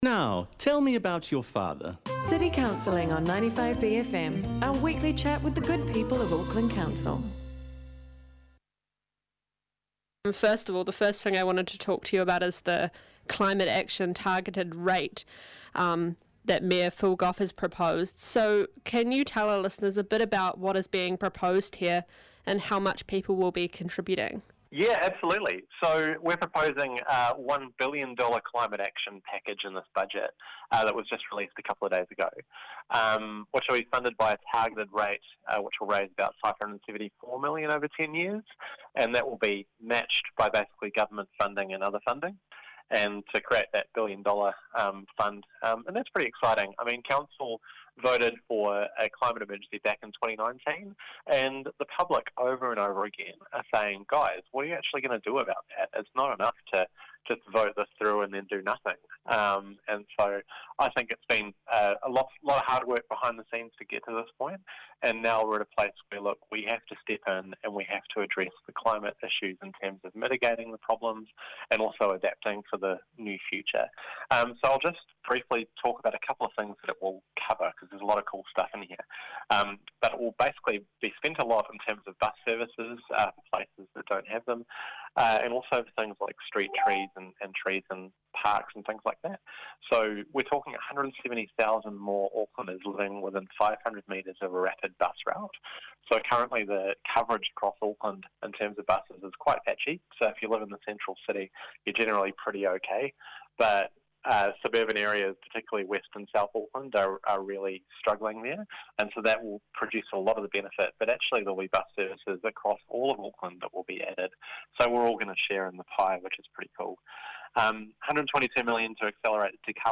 Here is that interview.